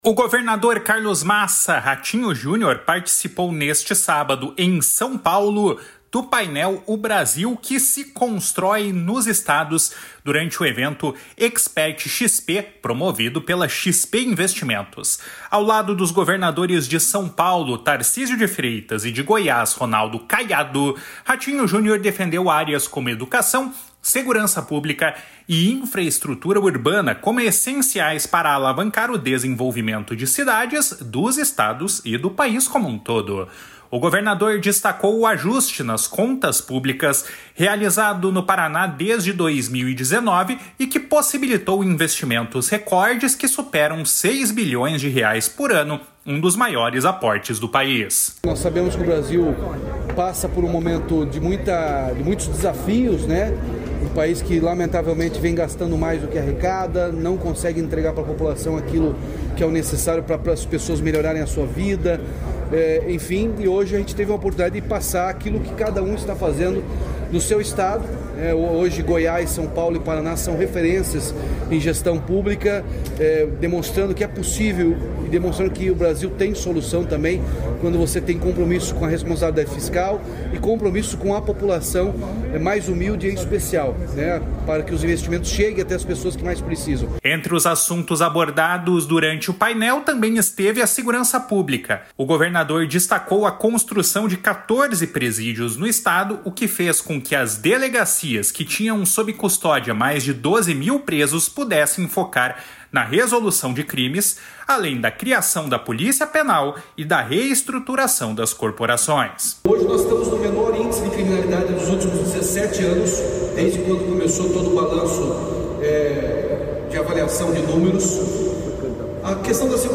Governador no Expert XP.mp3